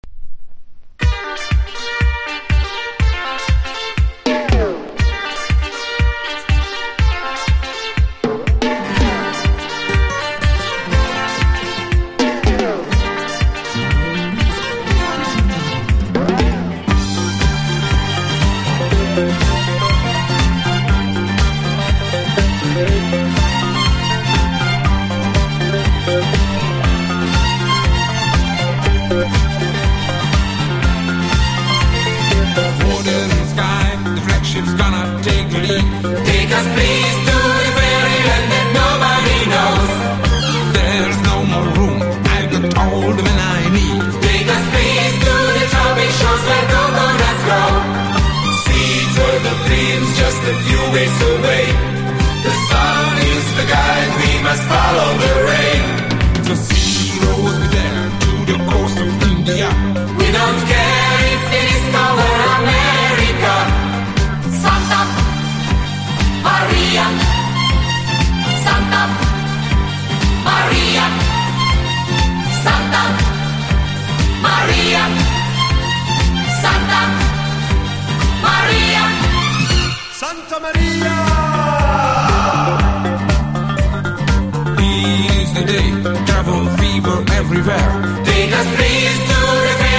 ダンサブルなディスコ・チューン満載な1枚！